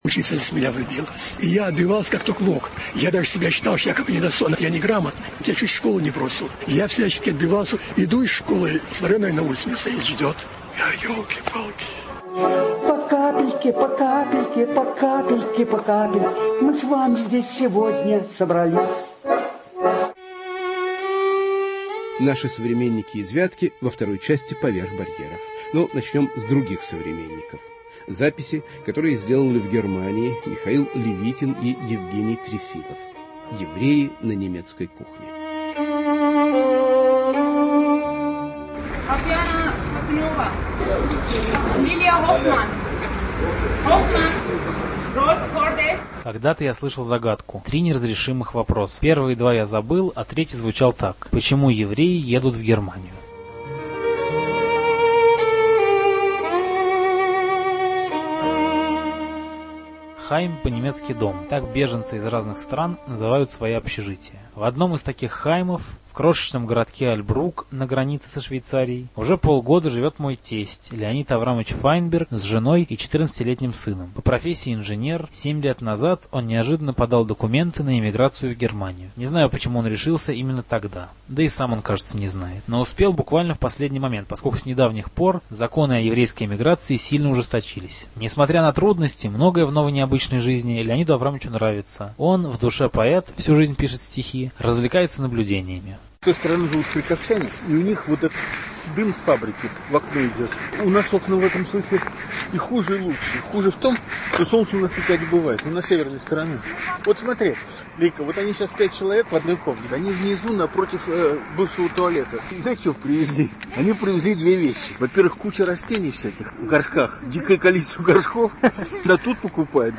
"Евреи на немецкой кухне" Разговоры с российскими и украинскими евреями, уехавшими в последние годы в Германию.